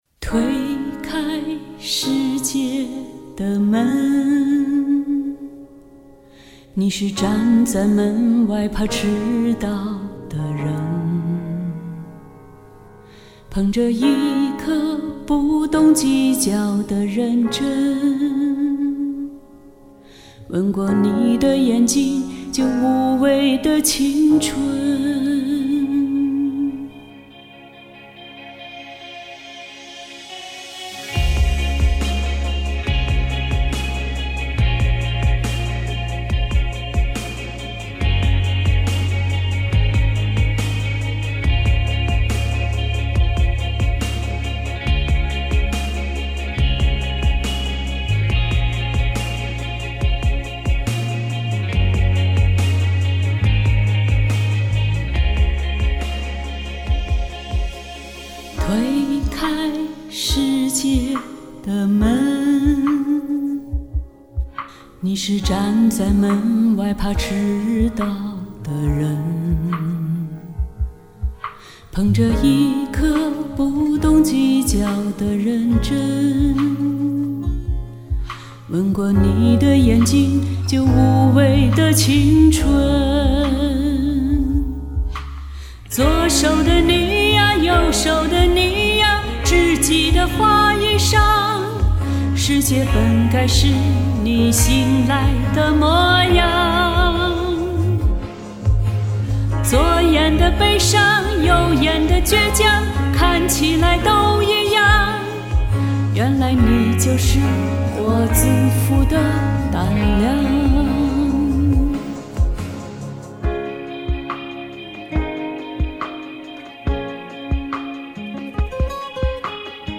翻唱：我